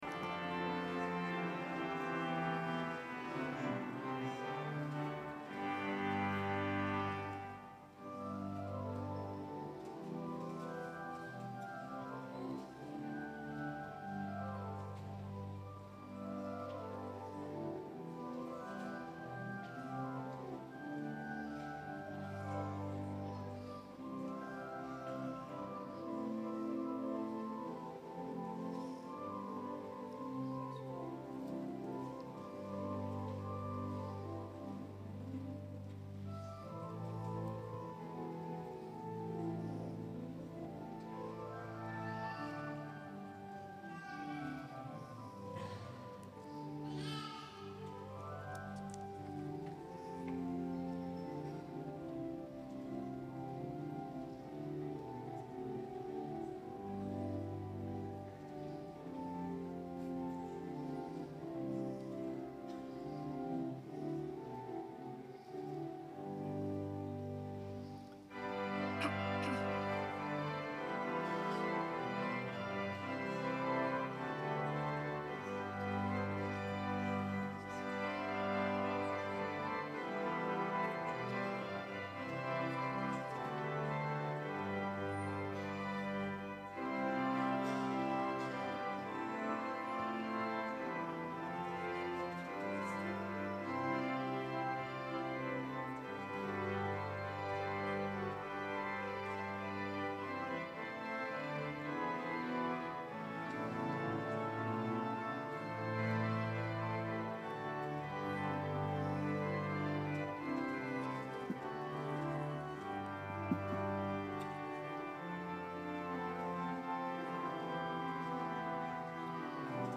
Écouter le culte entier (Télécharger au format MP3)